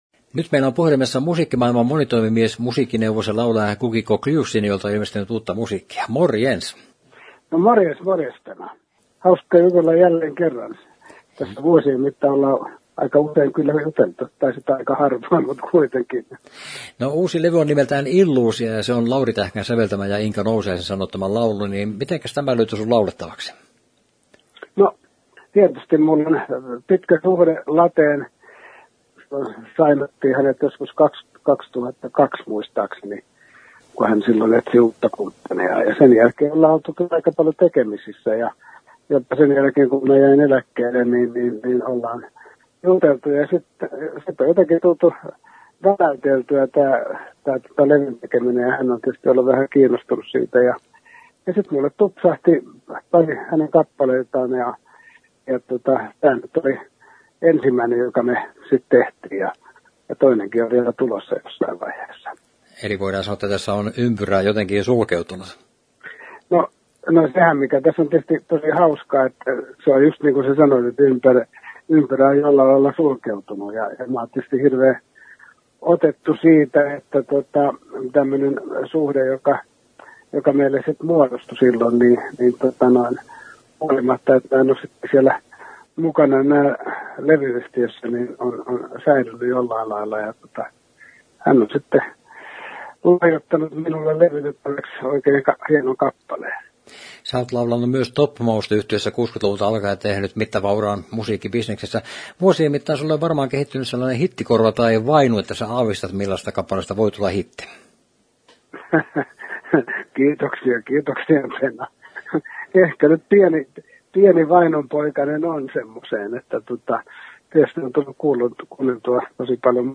Haastattelu, Henkilökuvassa, Viihdeuutiset, Yleinen